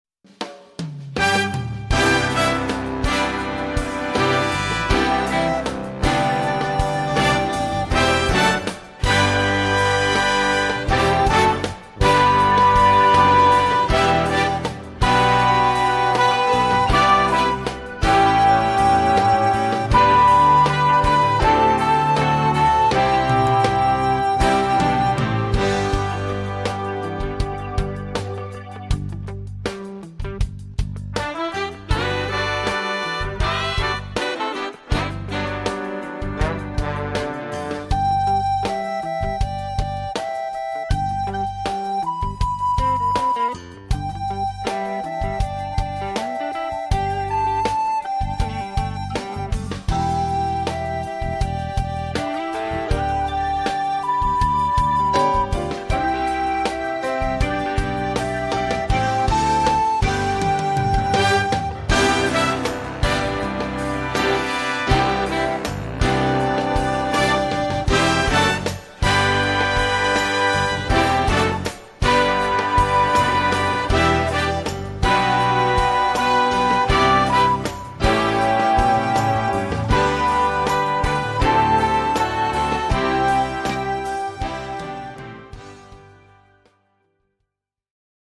• Some tracks contain either slower or faster audio files